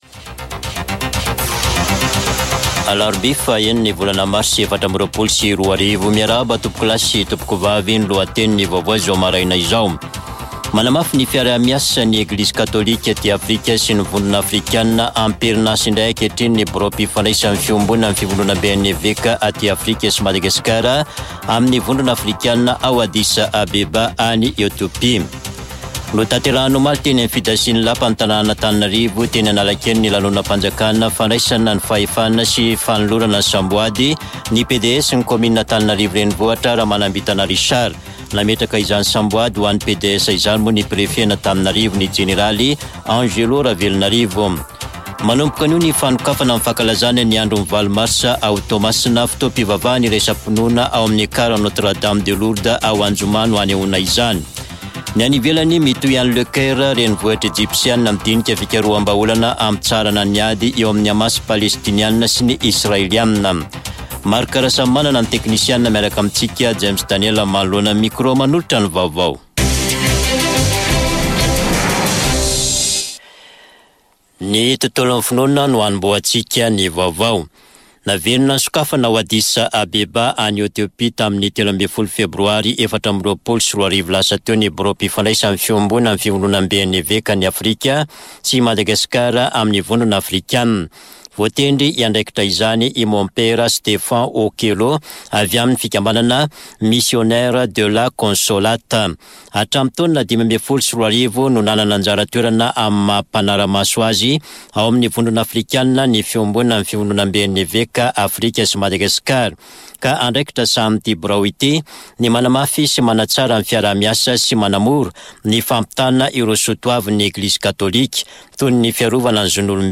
[Vaovao maraina] Alarobia 6 marsa 2024